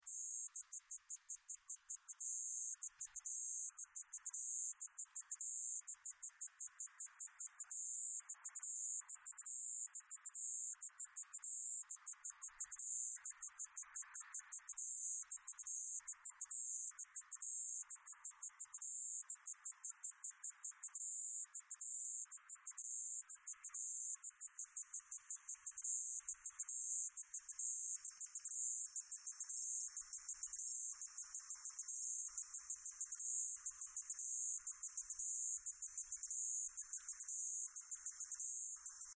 Insect Sounds ringtone free download
Sound Effects